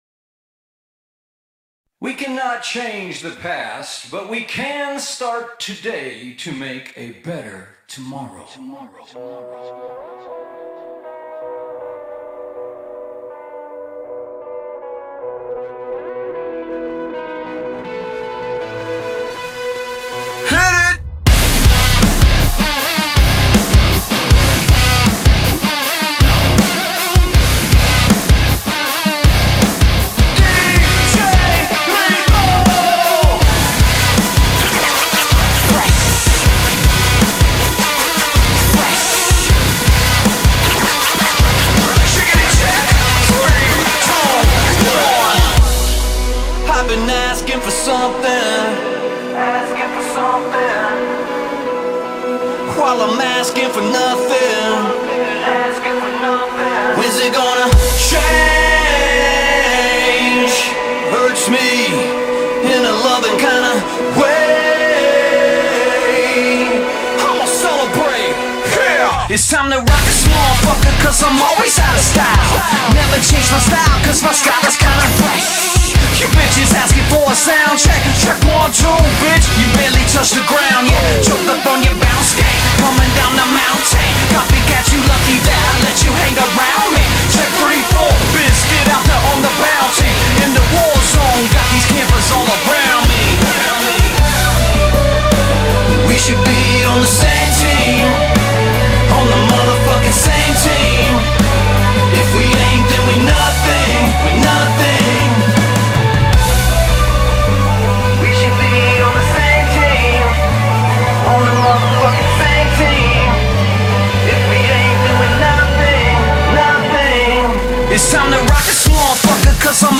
BPM20-79
Audio QualityCut From Video